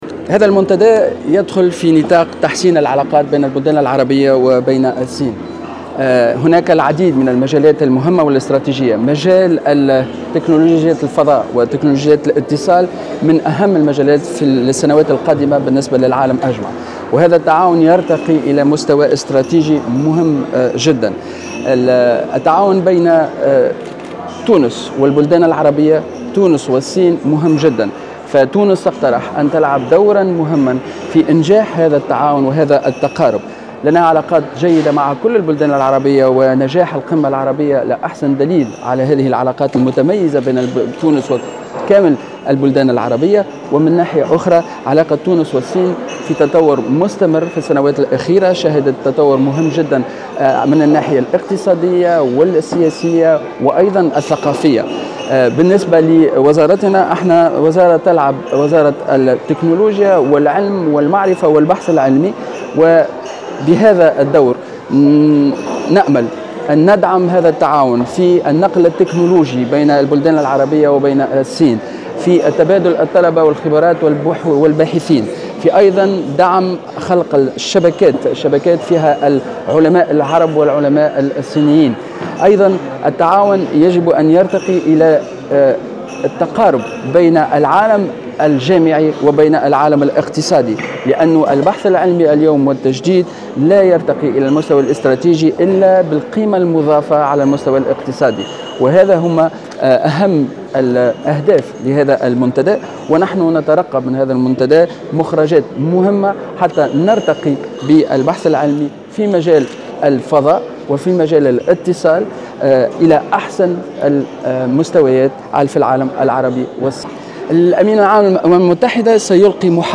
قال وزير التعليم العالي و البحث العلمي سليم خلبوس في تصريح لمراسلة الجوهرة "اف ام" على هامش الندوة المخصصة للدورة الثانية لمنتدى التعاون العربي الصيني اليوم الإثنين إن هذا المنتدى يدخل في نطاق تحسين العلاقات بين البلدين في عديد المجالات أهمها تكنولوجيات الفضاء و الاتصال .